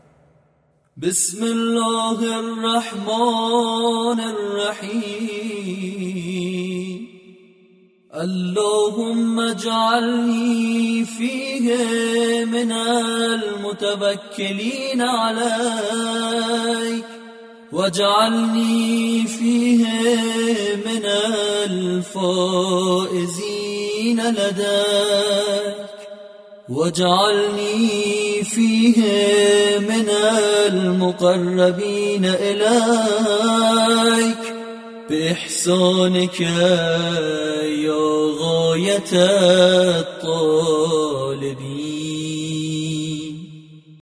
Ramazan ayının 10-cu gününün duası